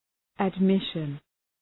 {əd’mıʃən}
admission.mp3